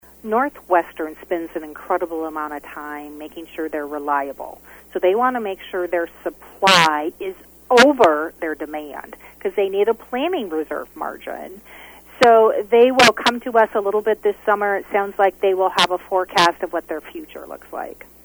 According to Fiegen the PUC is the watch dog’s for SD customers and the priority is to provide is to secure benefits to rate payers while insuring the investor owned utilities continue to provide safe and reliable energy service.  She said Northwestern is working on more plans for improvements to their infrastructure and customer base.